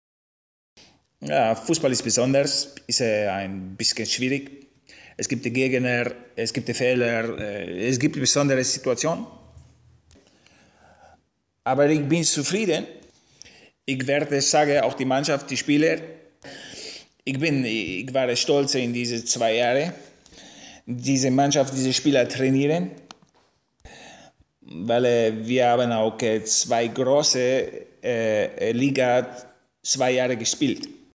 dunkel, sonor, souverän, markant, sehr variabel
Italienischer Akzent Probe
Doku, Comedy
Italian